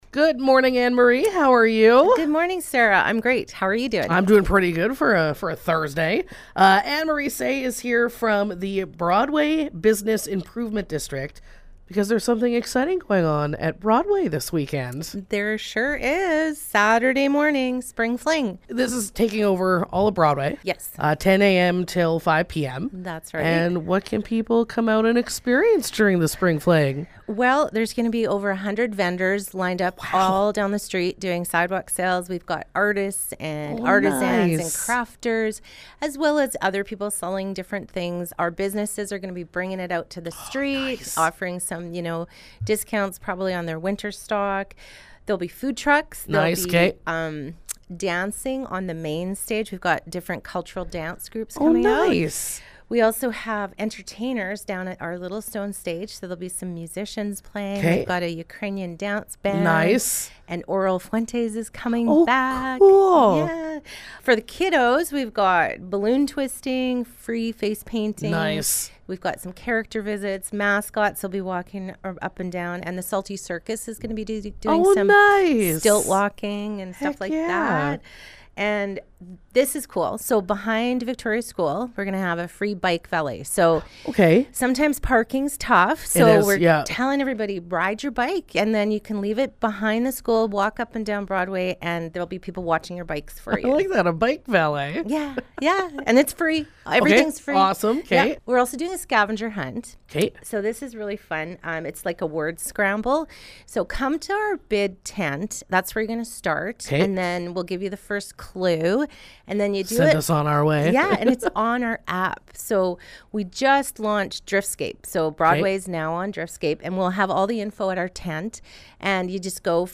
Interview: Spring Fling on Broadway Ave June 7th